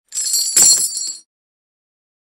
Звуки кандалов
На этой странице собраны звуки кандалов: звон цепей, скрежет металла, тяжелые шаги в оковах.